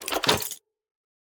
Minecraft Version Minecraft Version 25w18a Latest Release | Latest Snapshot 25w18a / assets / minecraft / sounds / block / vault / insert.ogg Compare With Compare With Latest Release | Latest Snapshot